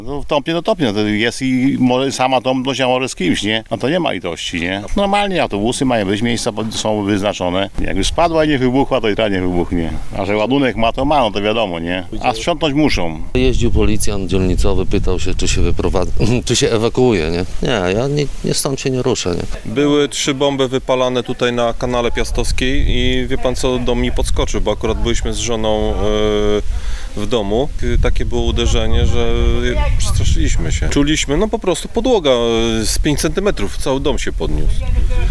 O odczucia mieszkańców pytał nasz reporter.